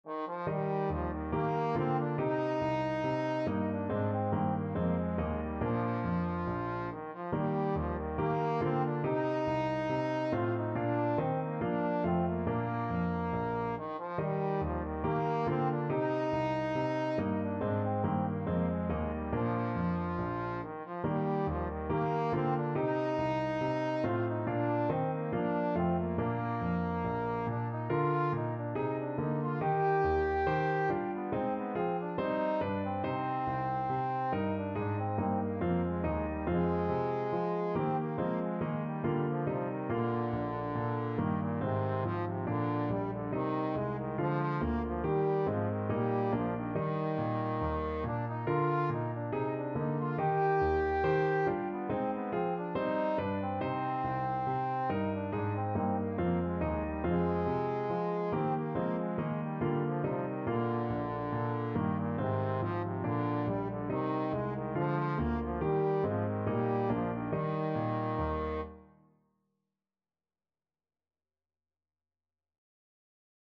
Trombone
Eb major (Sounding Pitch) (View more Eb major Music for Trombone )
4/4 (View more 4/4 Music)
Classical (View more Classical Trombone Music)